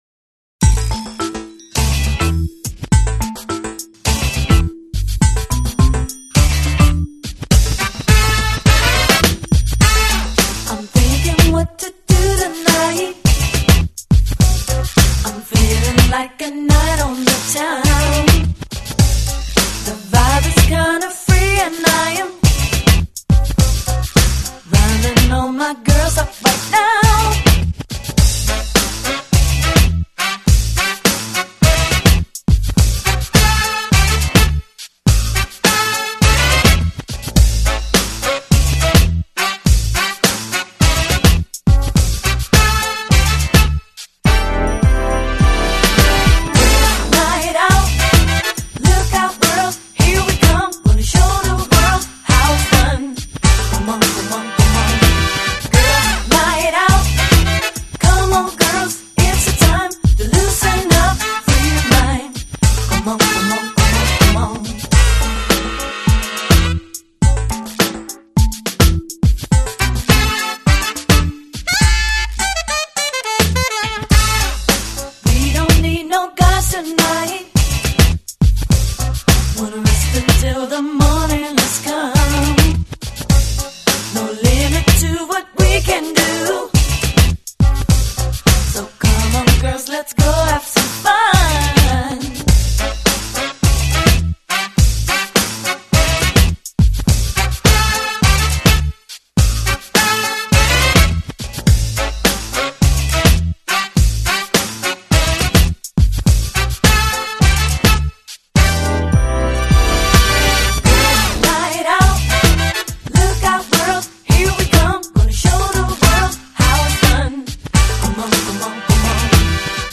【爵士萨克斯】
类型:Jazz